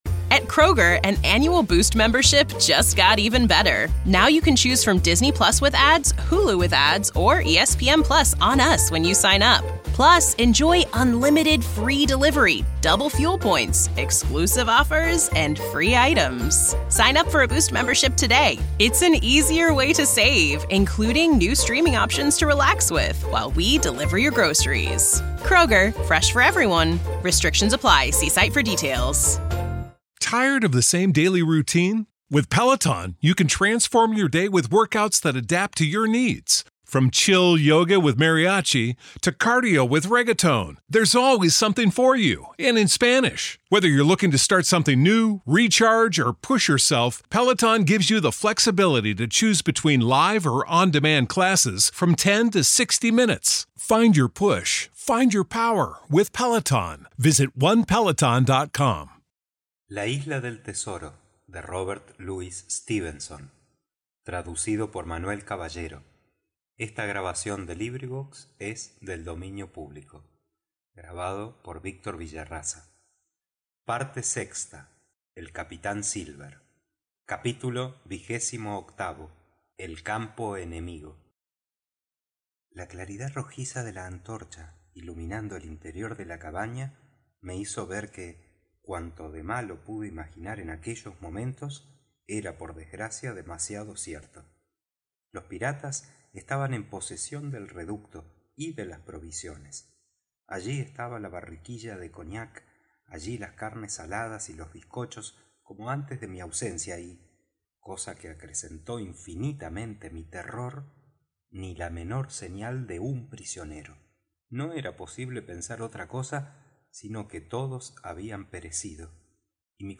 AudioLibros